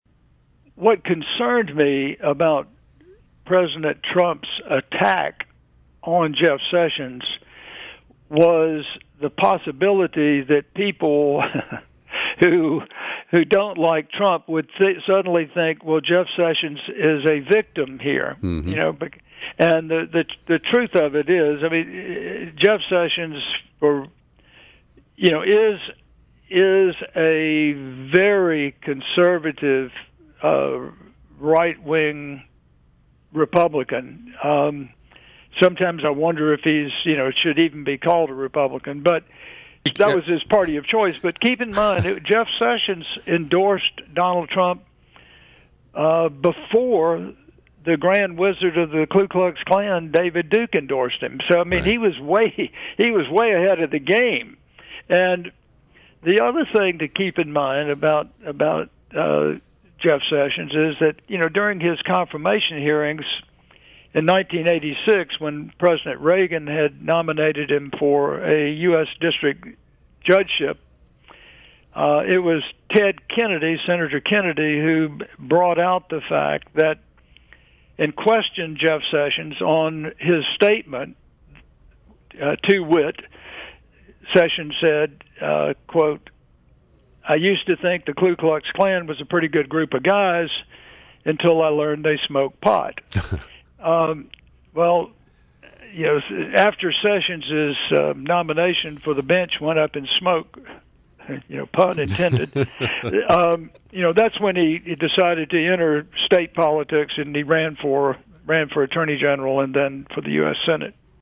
In-Depth Interview: Gov. Don Siegelman Comments on Sessions and Other Corrupt Alabama Leaders in First Post-Prison Interview